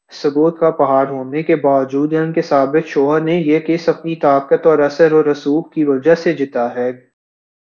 deepfake_detection_dataset_urdu / Spoofed_TTS /Speaker_13 /261.wav